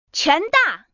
Index of /fujian_bm_wdd/update/3173/res/sfx/common_woman/